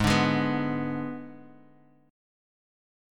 G#dim chord